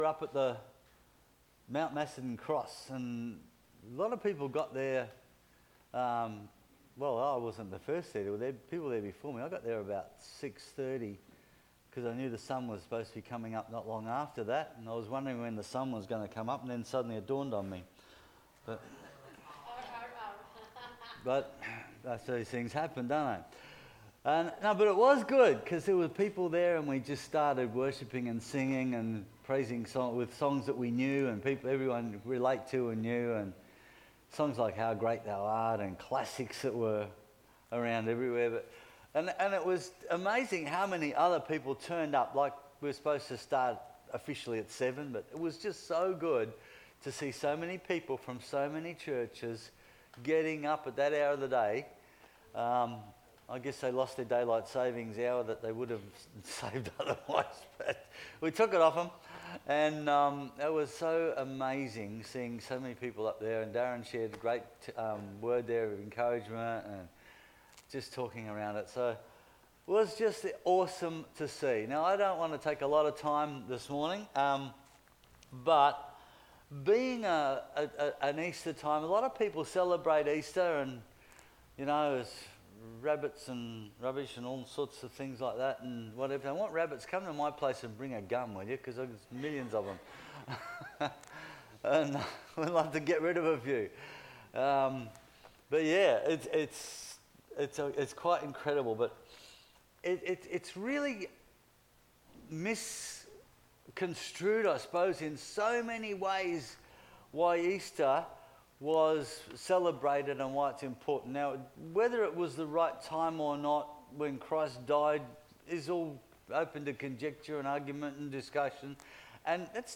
Easter Sunday Service message